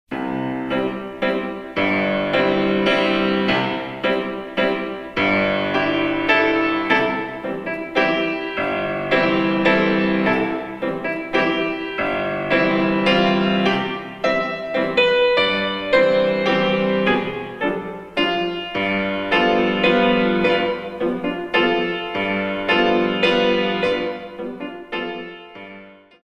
In 3
128 Counts